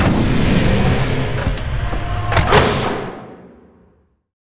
taxiopen.ogg